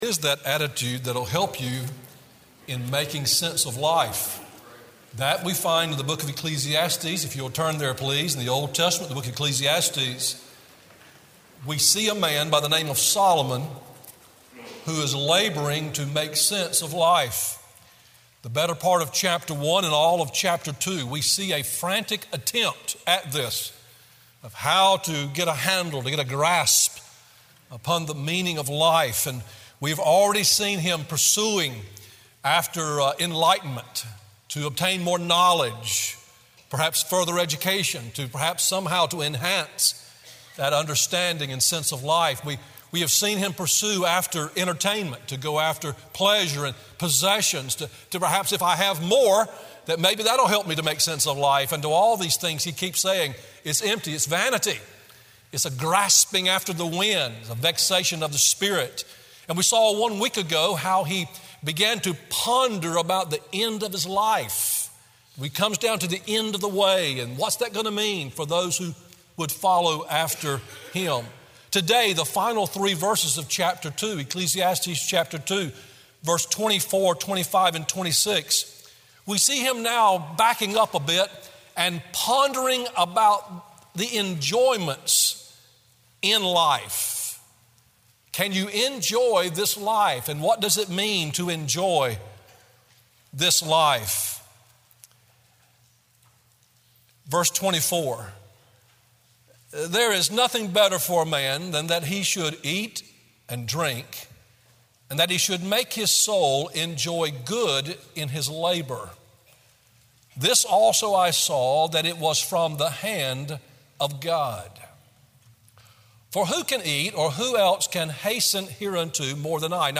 Sermon Audios/Videos | Tar Landing Baptist Church
Morning WorshipEcclesiastes 3:1-8